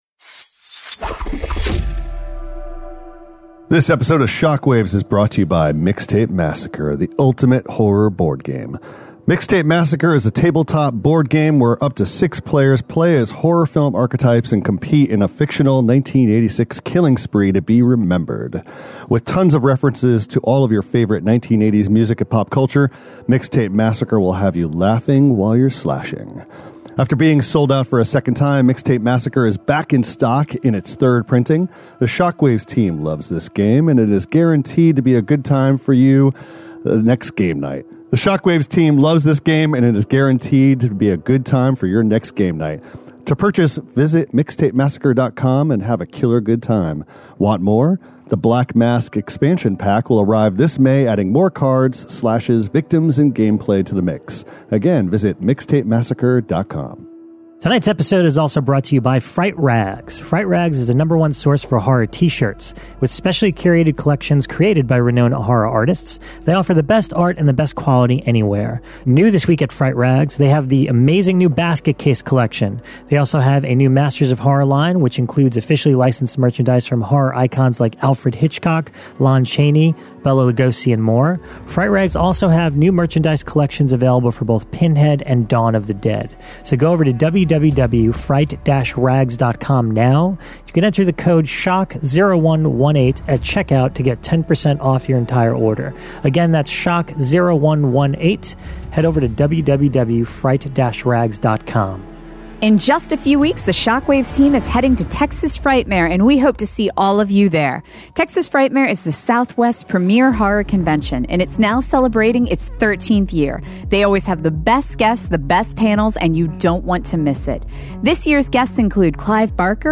Episode 93: Live from The Chattanooga Film Festival with Joe Bob Briggs!